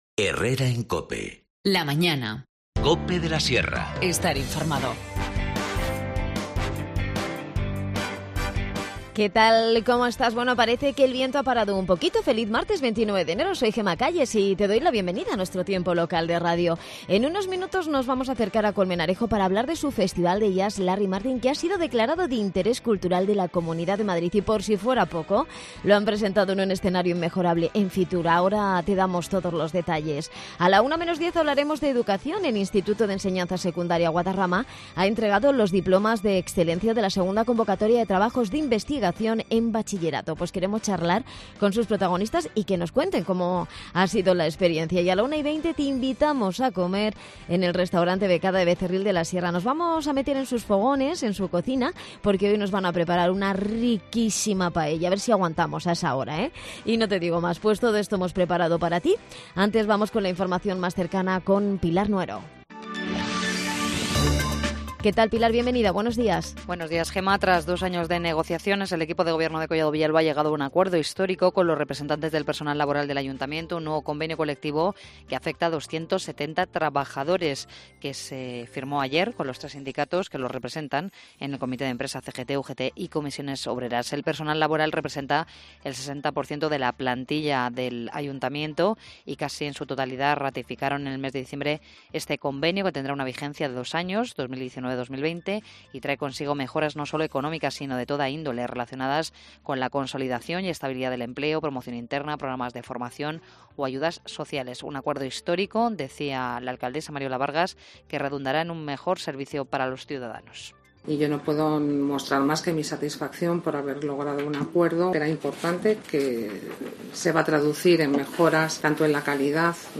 Redacción digital Madrid - Publicado el 29 ene 2019, 12:48 - Actualizado 15 mar 2023, 22:00 1 min lectura Descargar Facebook Twitter Whatsapp Telegram Enviar por email Copiar enlace El Festival de Jazz Larry Martin de Colmenarejo ha sido decalarado de interés cultural de la Comunidad de Madrid. Nieves Roses, alcaldesa de la localidad, nos cuente sus impresiones.